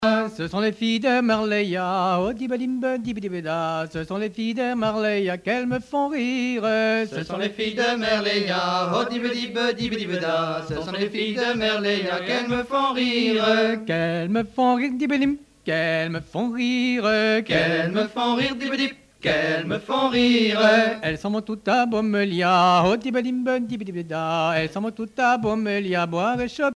danse : riqueniée ;